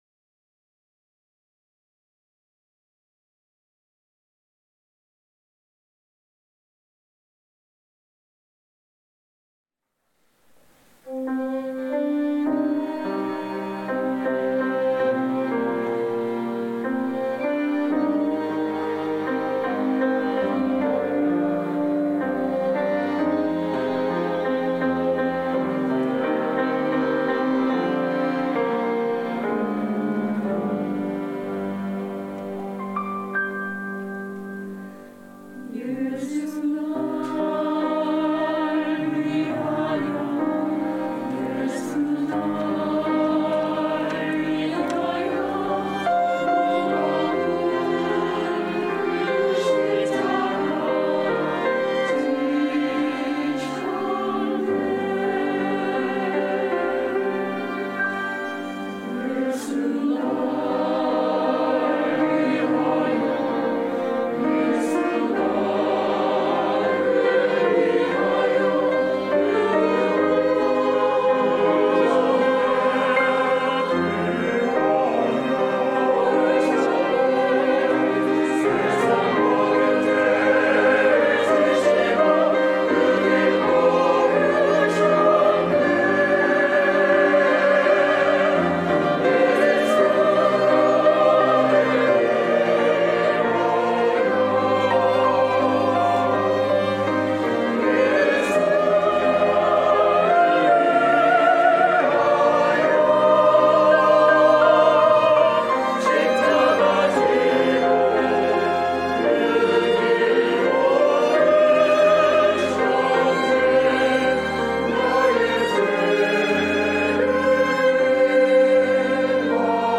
시온